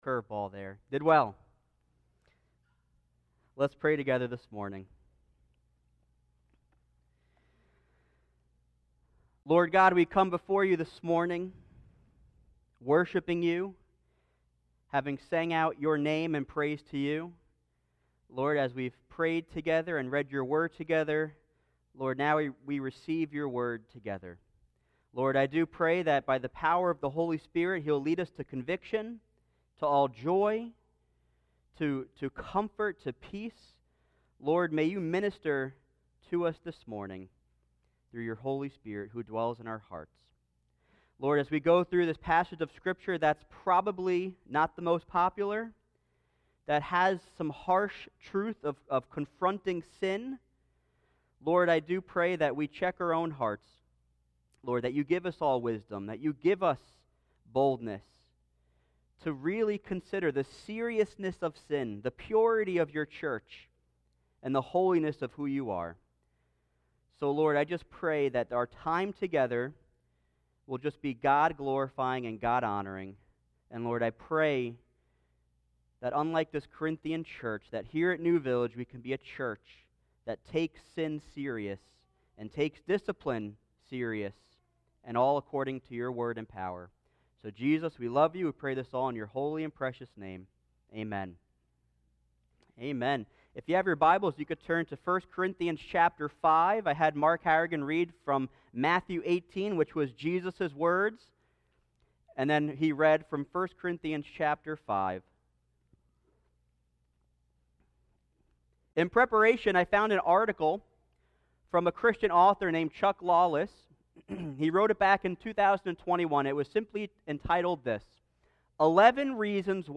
Sermons Archive - New Village Church